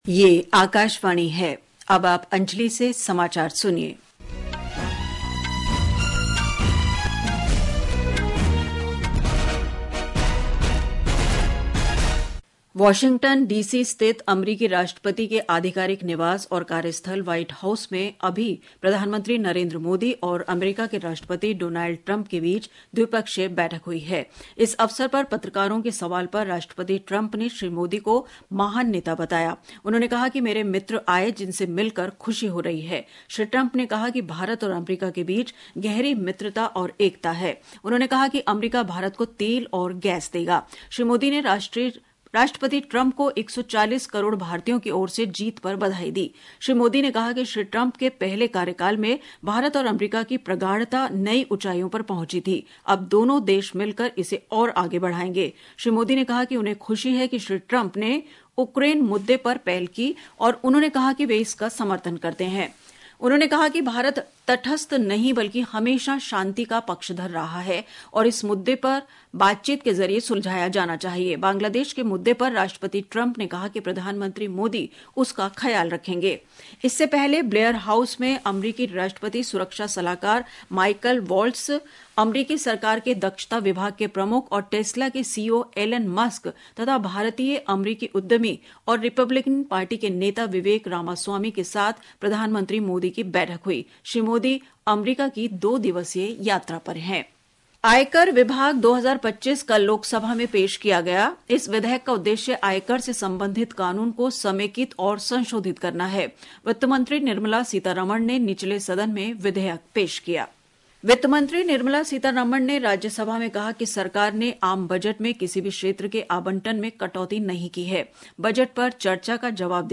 National Bulletins
प्रति घंटा समाचार